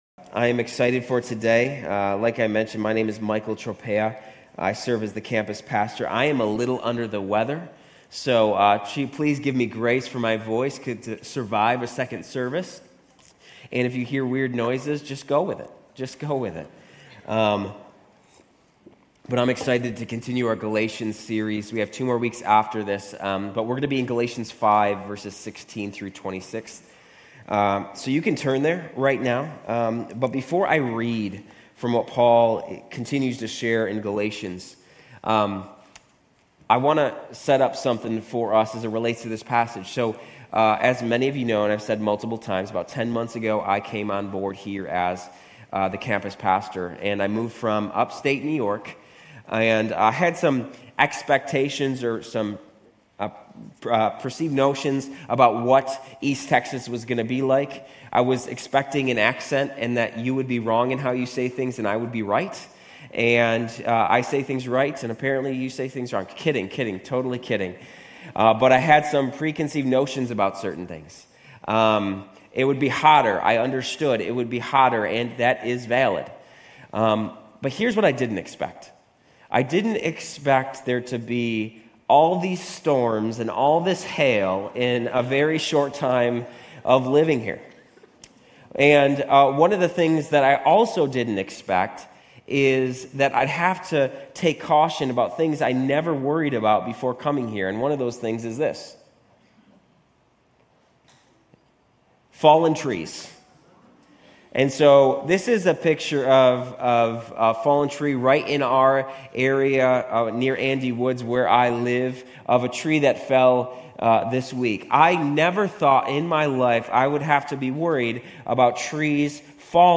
Grace Community Church University Blvd Campus Sermons Galatians 5:16-26 Jun 03 2024 | 00:39:53 1x 00:00 / 00:39:53 Subscribe Share RSS Feed Share Link Embed